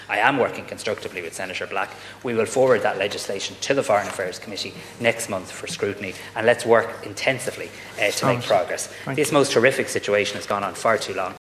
Speaking in the Dail chamber, Tanaiste Simon Harris said provision would result in too many legal complications: